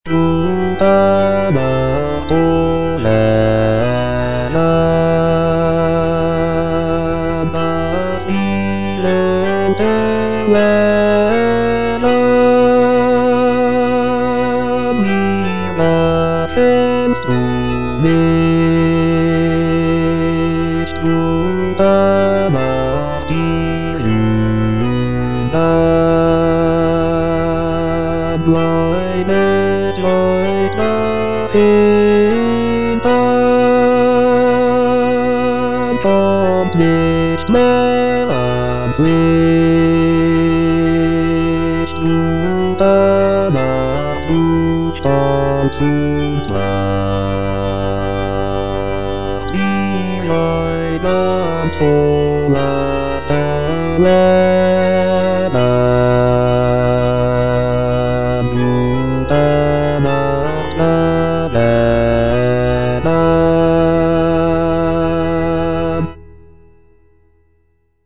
Cantata
Bass (Voice with metronome) Ads stop